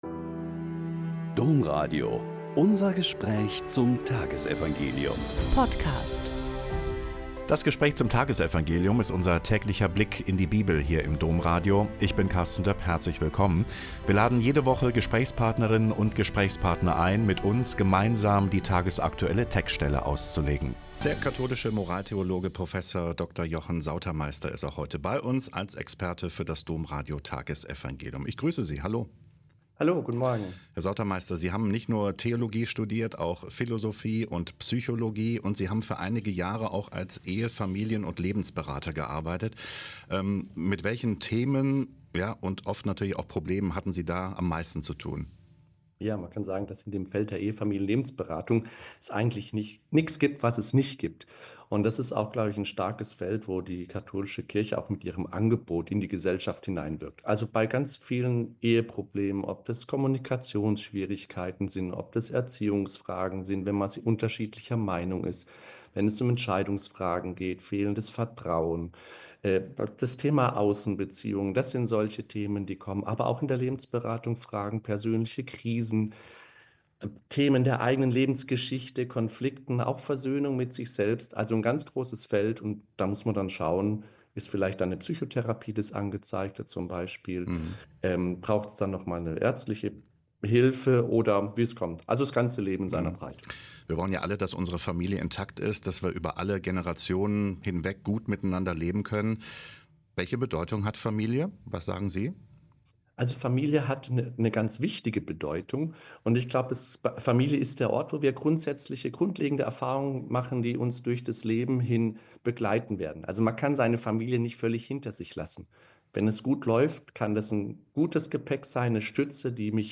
Lk 11,14-23 - Gespräch